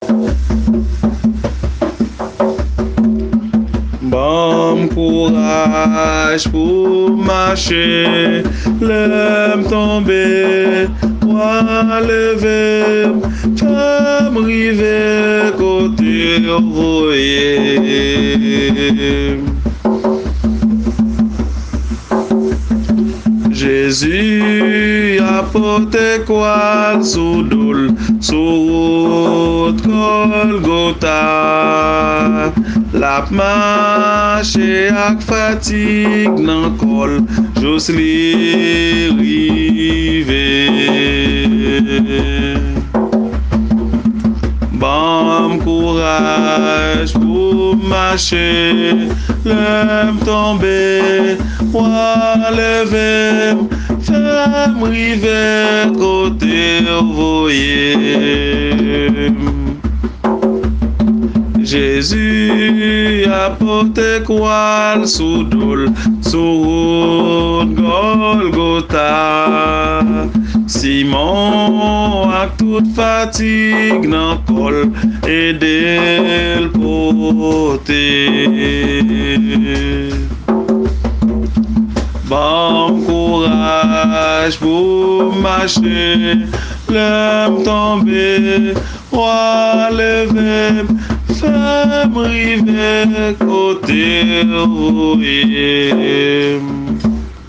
Kantik Kréyòl